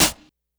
Snare_29.wav